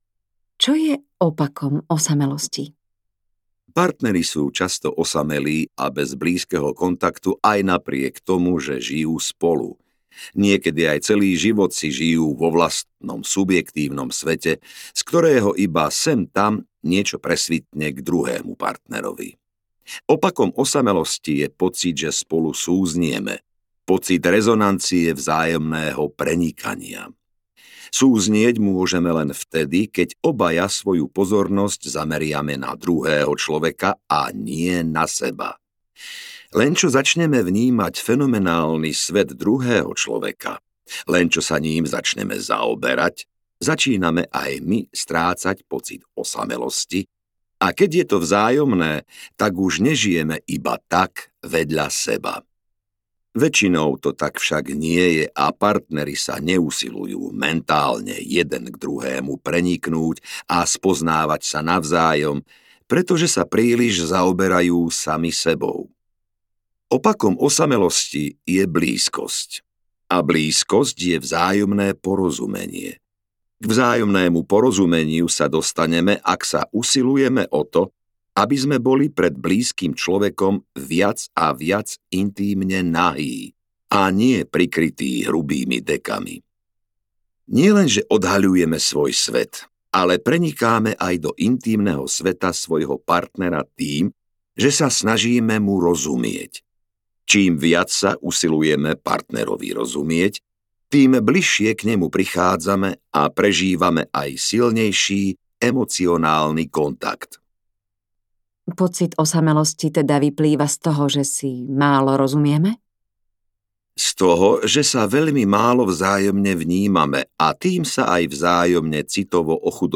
Umenie blízkosti audiokniha
Ukázka z knihy